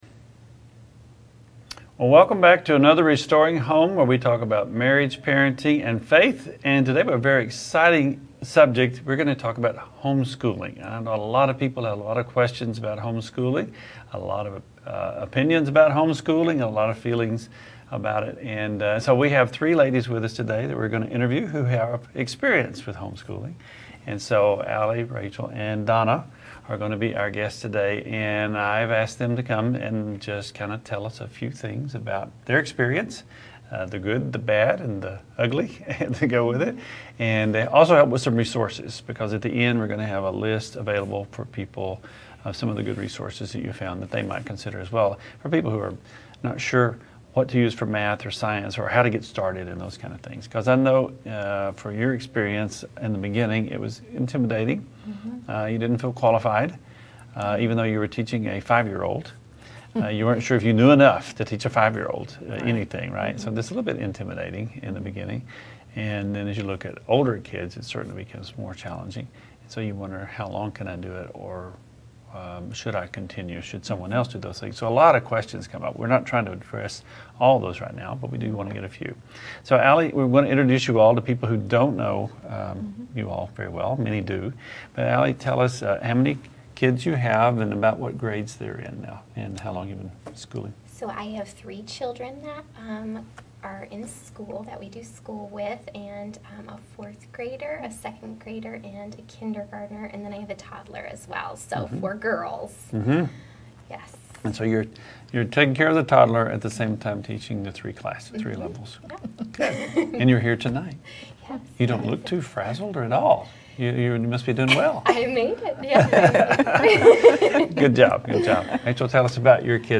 Three mothers share their experiences for parents considering homeschooling as public systems become increasingly hostile spaces.
Three mothers share their experiences with homeschooling – both the rib-ticklers and the tear-jerkers.